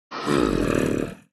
僵尸村民：低吼
Minecraft_zombie_villager_say1.mp3